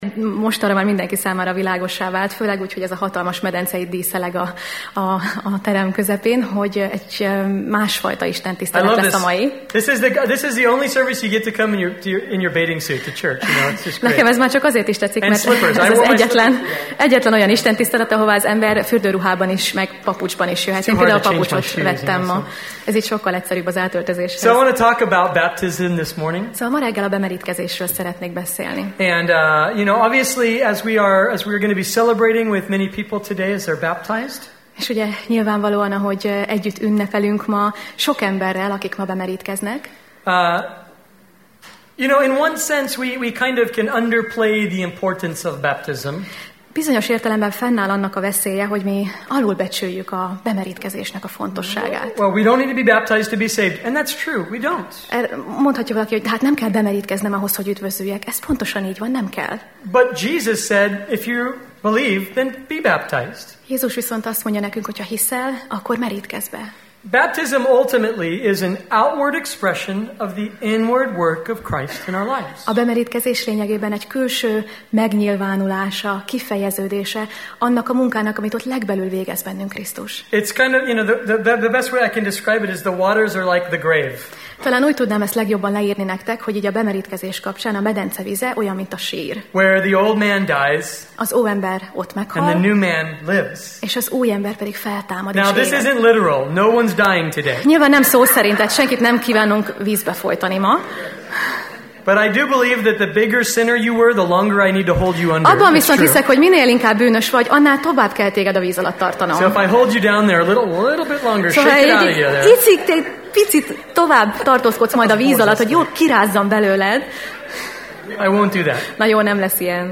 Alkalom: Különleges alkalom